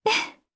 Isaiah-Vox_Happy1_jp.wav